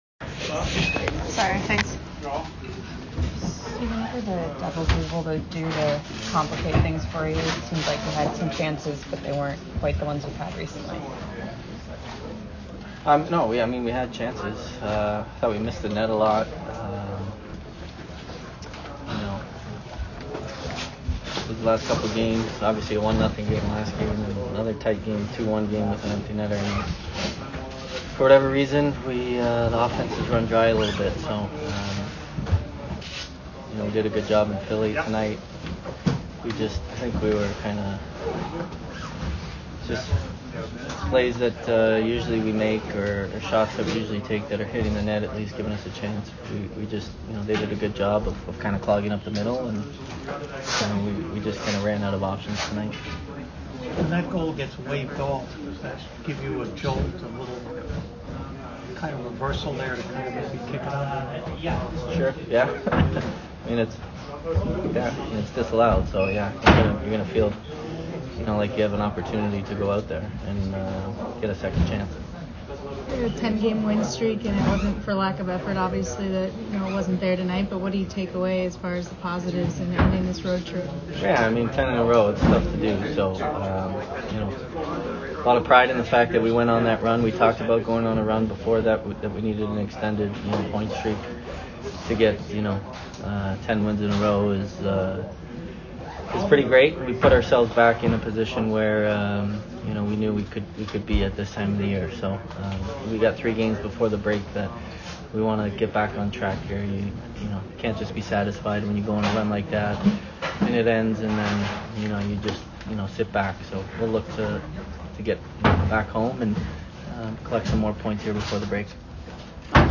Steven Stamkos Post - Game At New Jersey Jan. 12, 2020